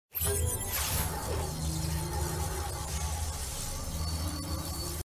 Открытие портала